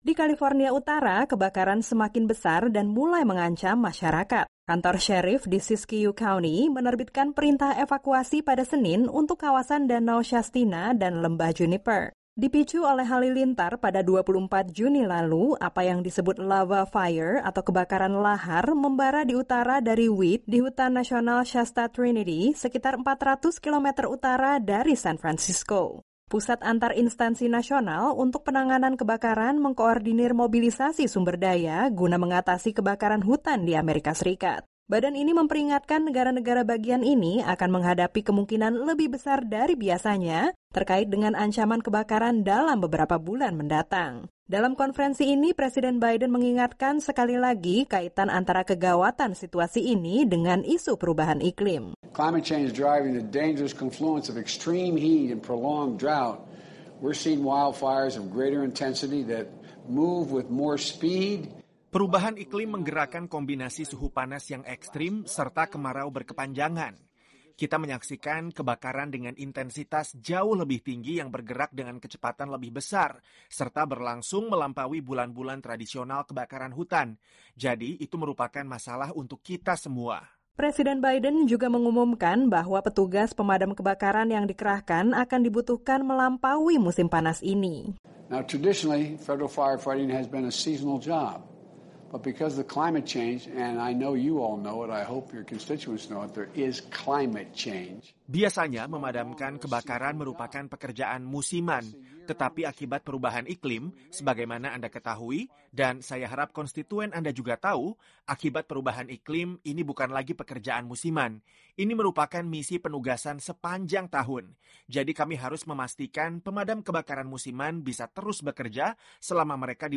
Ancaman kebakaran hutan di delapan negara bagian di Amerika barat semakin besar, dan Presiden Biden menyelenggarakan konferensi video dengan para gubernur di sana. Laporan selengkapnya telah disiapkan tim VOA.